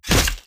Melee Weapon Attack 24.wav